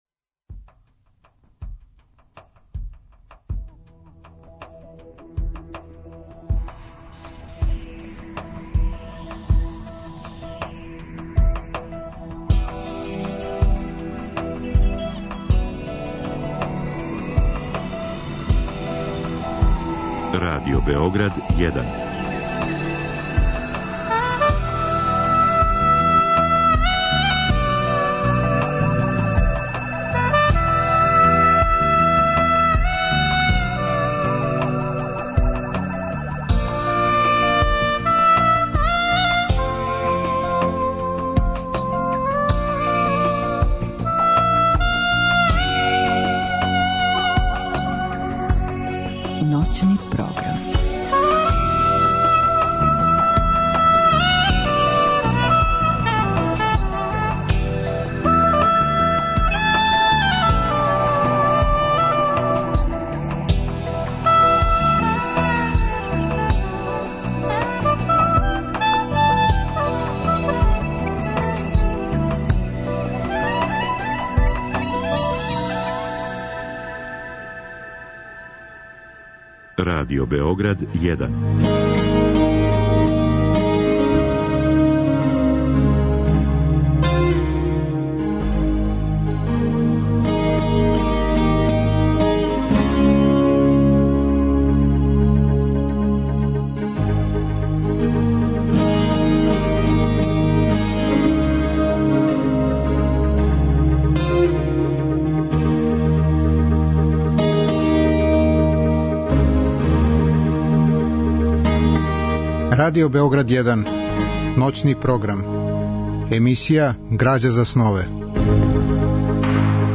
Разговор и добра музика требало би да кроз ову емисију и сами постану грађа за снове. Гост емисије је писац Владимир Пиштало.